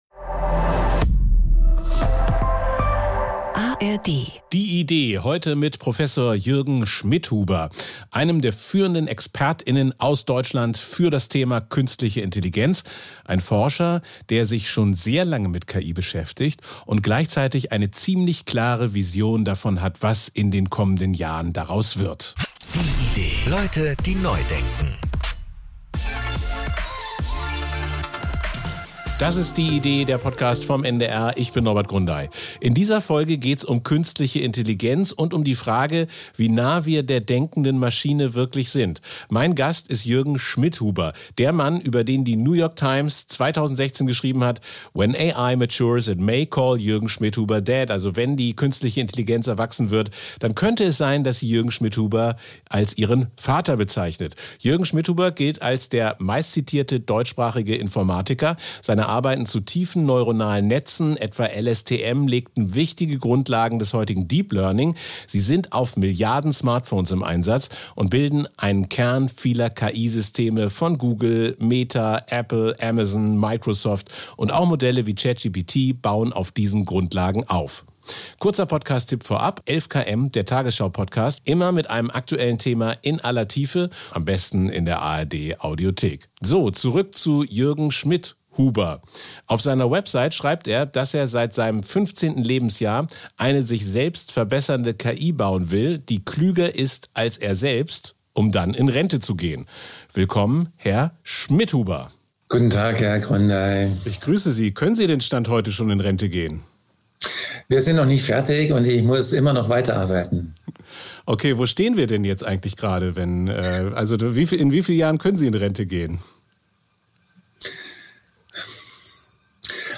Podcast des NDR